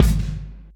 Medicated Kick 23.wav